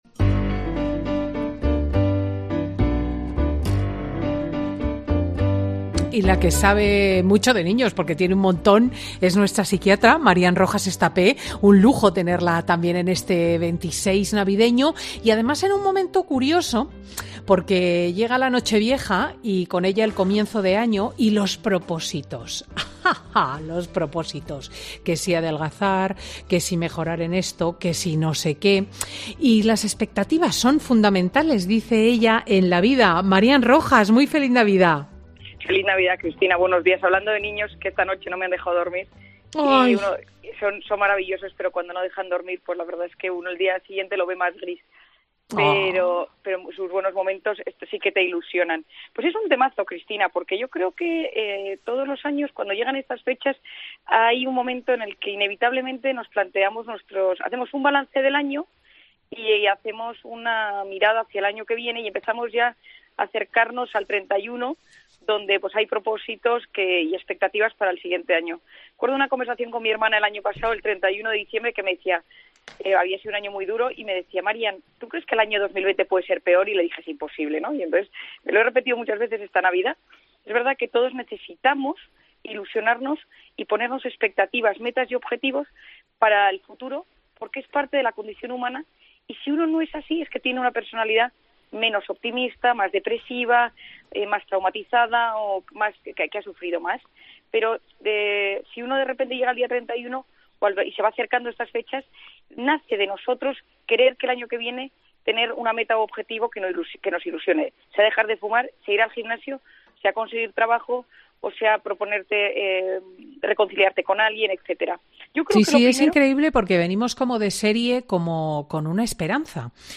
La psiquiatra de Fin de Semana con Cristina recomienda las mejores pautas para llegar a 2021 con propósitos y no caer rendido a las primeras